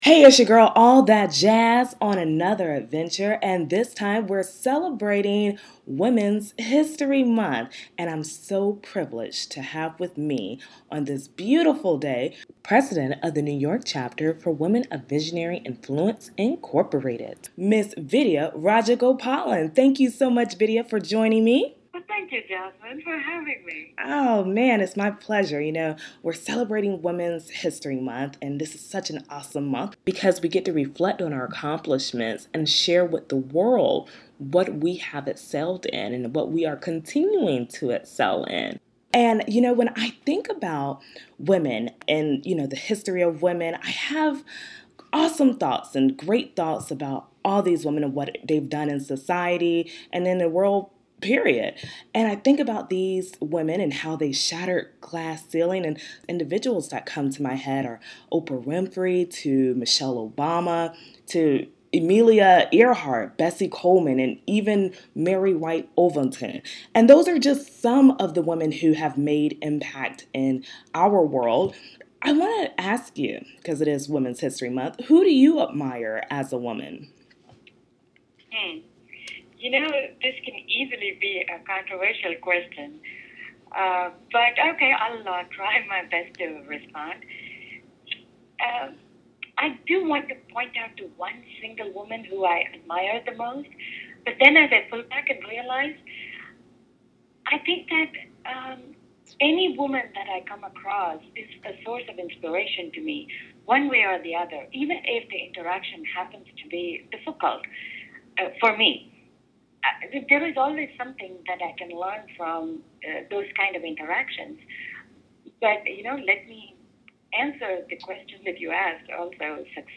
A One On One Conversation